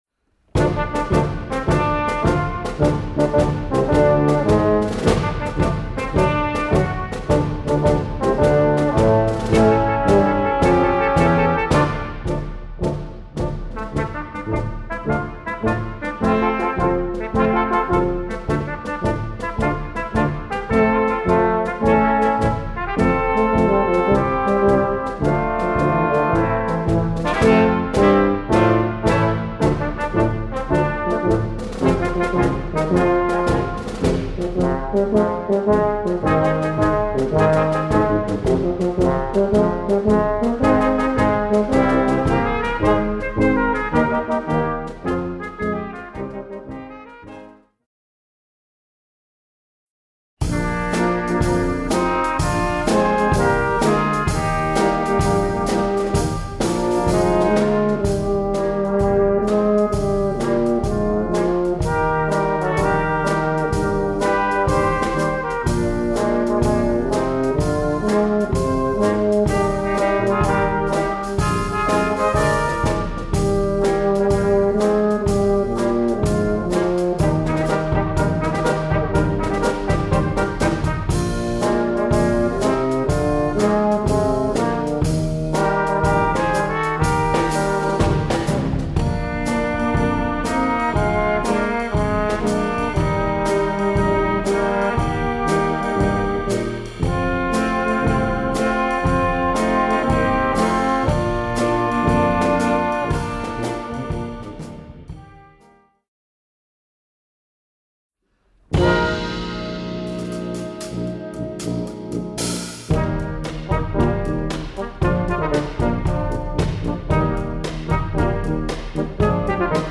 2 _ facile _einfach _ Easy
Ensemble 5 voix Flex
Young Band/Jugend Band/Musique de jeunes